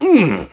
pain_2.ogg